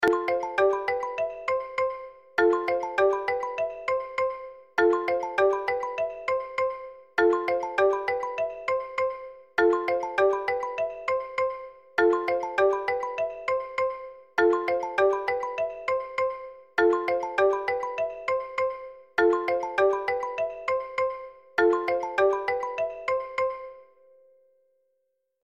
دانلود صدای تلفن 6 از ساعد نیوز با لینک مستقیم و کیفیت بالا
جلوه های صوتی